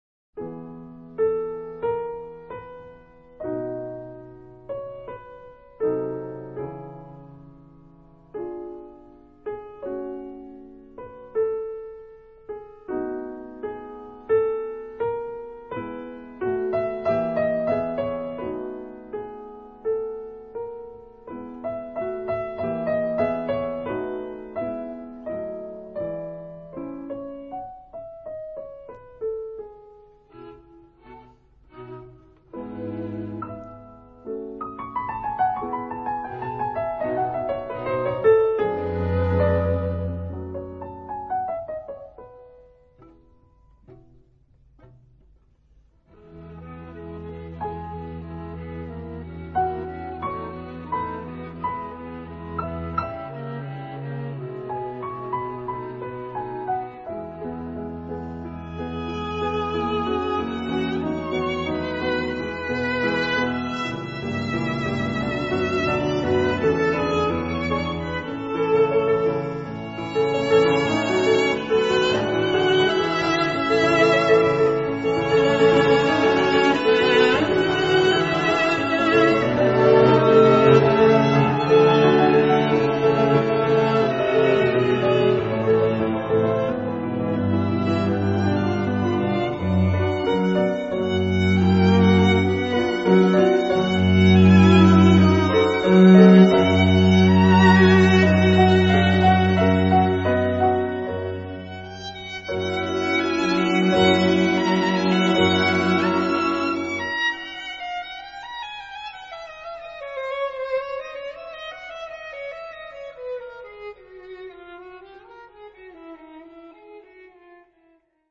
Cuarteto para piano,
Andante. 0.7 MB.